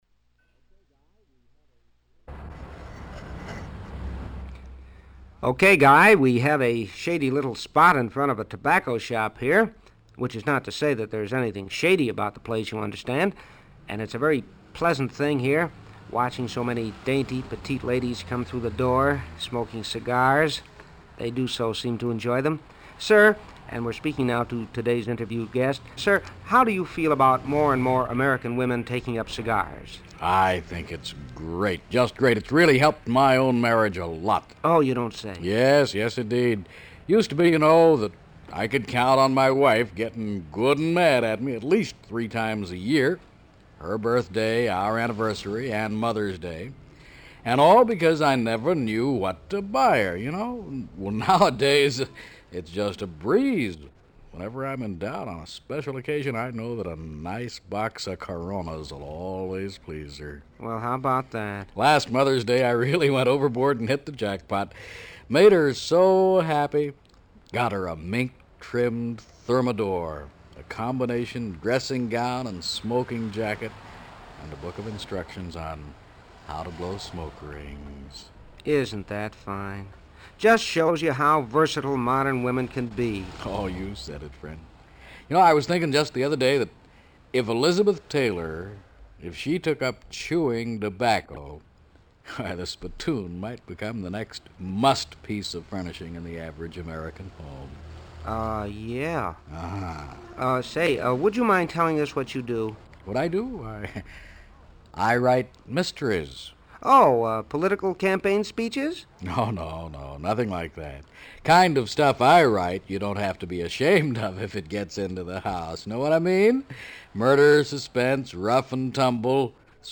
Walter P. Reuther Digital Archive · Man on the Street skit · Omeka S Multi-Repository